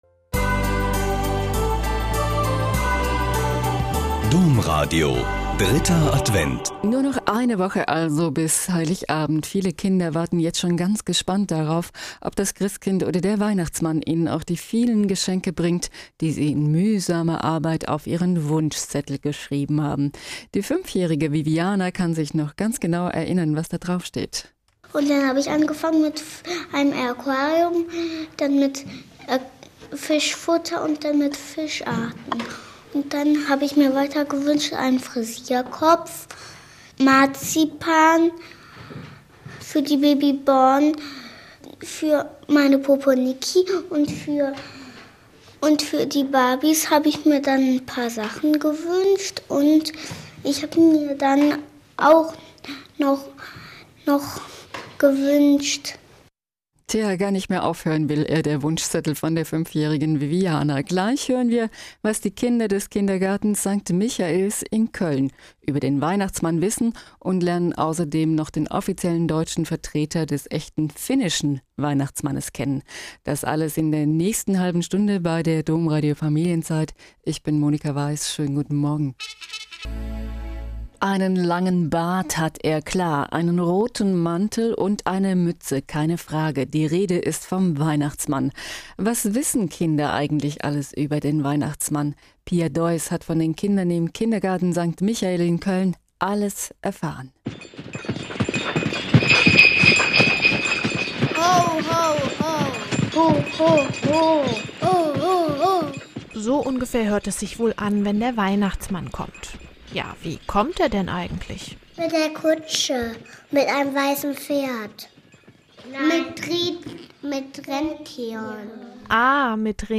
Mitschnitt